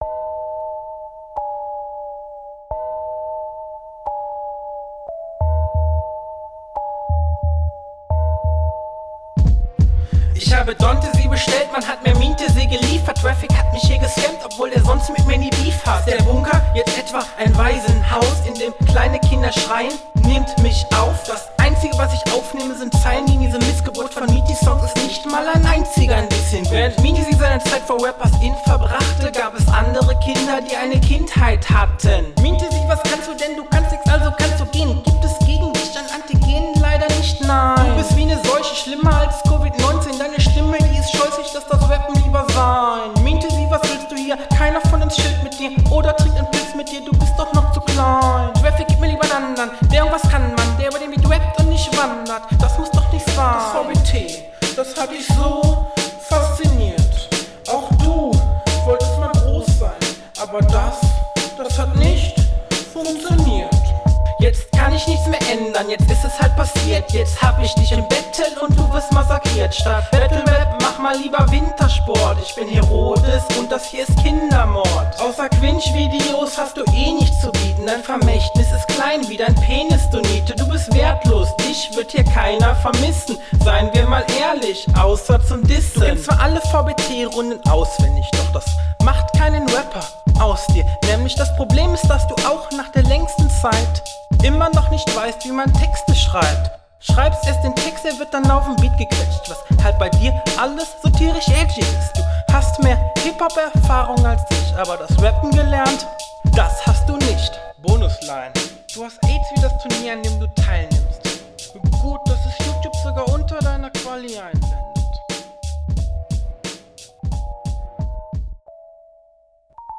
Flow: Der Flow ist gut, aber Stimmeinsatz und Deliviry schwächen das halt alles bisschen ab. …
Flow: Solider kontrollierter flow außer am ende der hook, das wirkt etwas verhaspelt. Manchmal schöne …